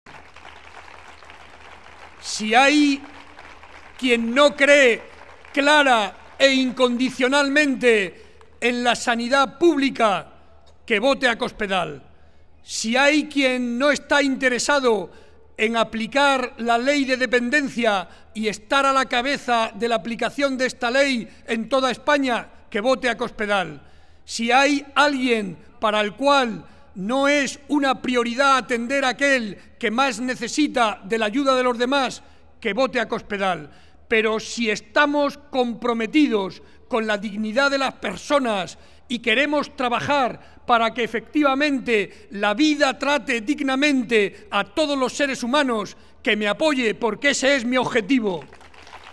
«Si hay quien no cree en la Sanidad pública, ni en la Ley de Dependencia, ni en la ayuda a los que más lo necesitan que vote a De Cospedal. Pero si lo que queremos es trabajar para que la vida trate con dignidad a todas la personas, que me vote porque ese es mi objetivo», afirmó el presidente de Castilla-La Mancha y candidato a la reelección José María Barreda hoy en Caudete en el paseo Luis Golf ante más de 800 vecinos de la localidad.